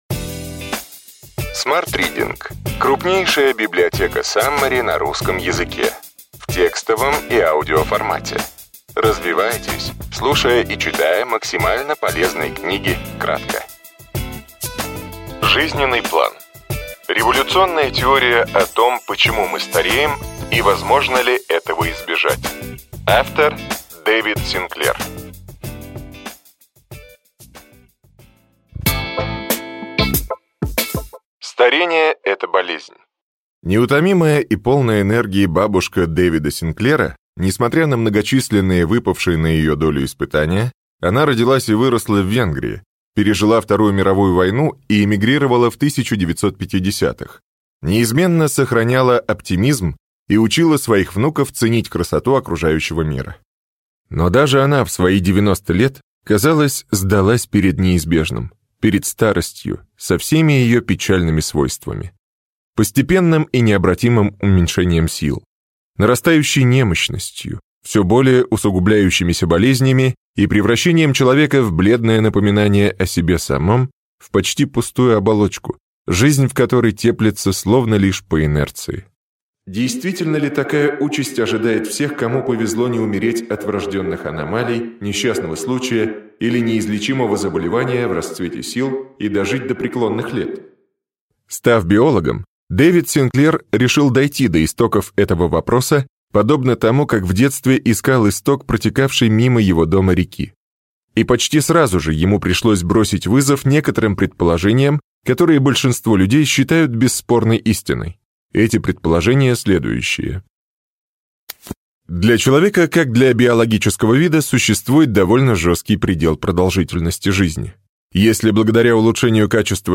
Аудиокнига Ключевые идеи книги: Жизненный план. Революционная теория о том, почему мы стареем и возможно ли этого избежать.